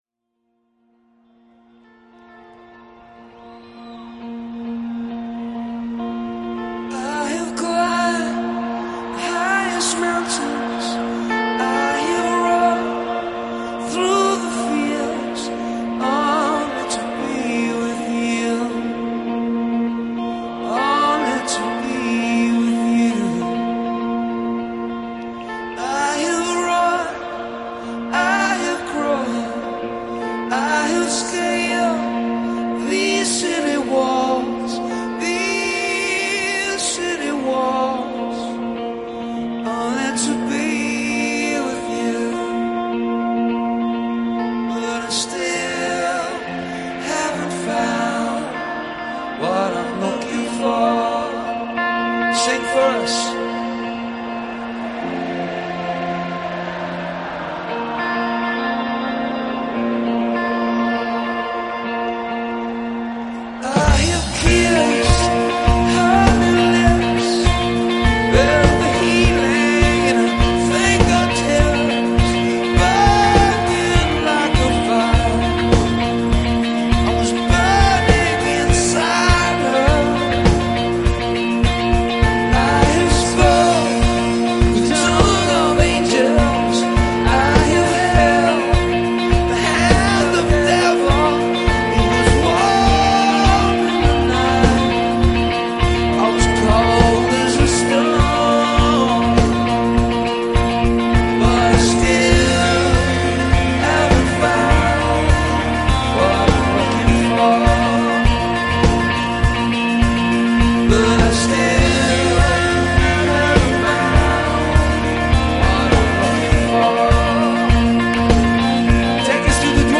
уникальных ритмических рисунков